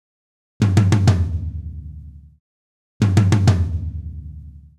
Drumset Fill 10.wav